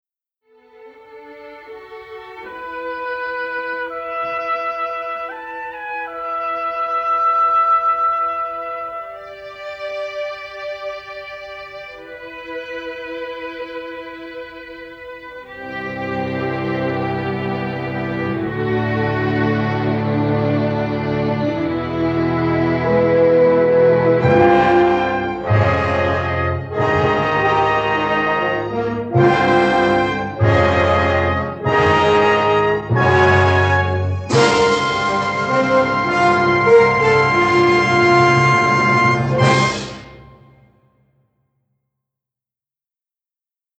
western score
complete score mastered in mono from print takes